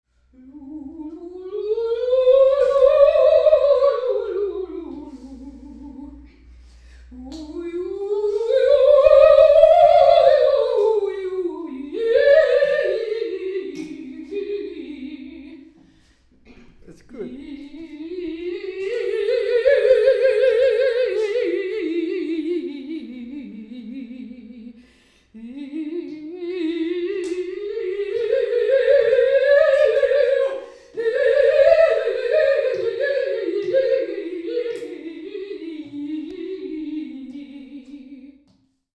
TÄGLICHE STIMMÜBUNGEN
PERFORMANCE